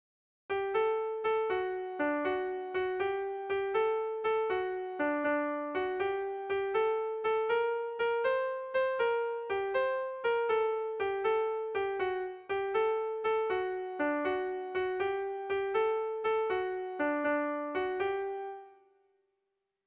Sentimenduzkoa
AABDAA